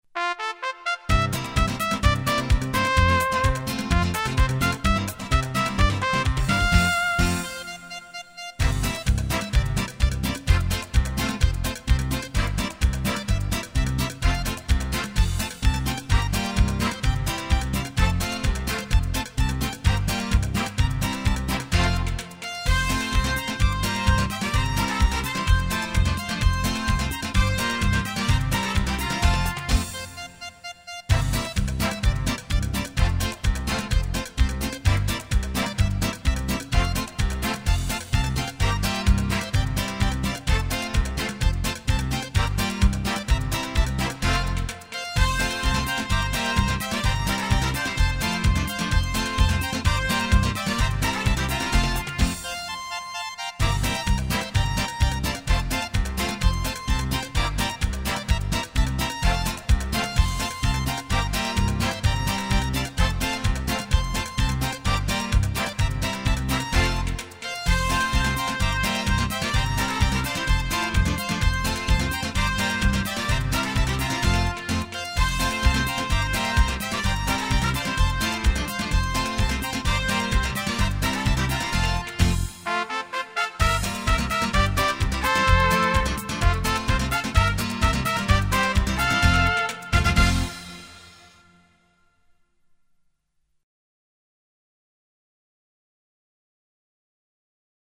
Canon
Version instrumentale :